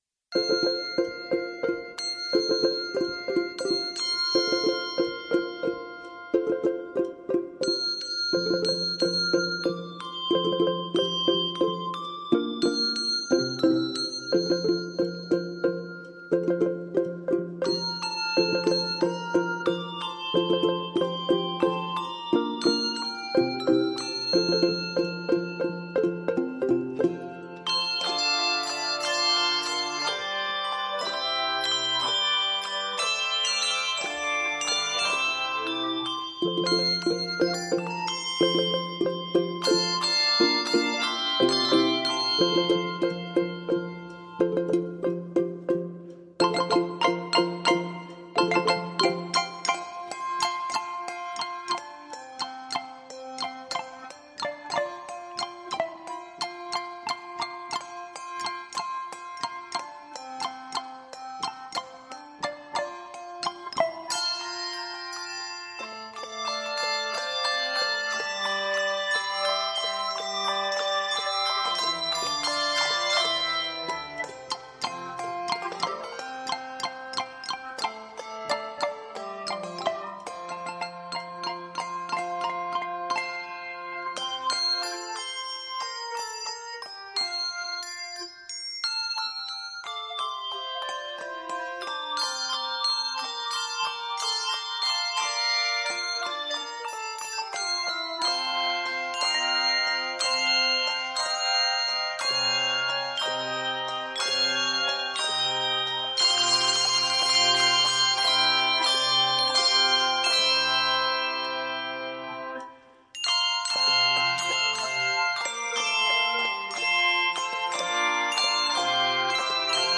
Key of F Major.